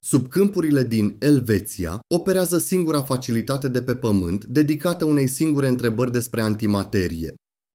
Audio Briefing
AI-narrated intelligence briefings for executives on the move.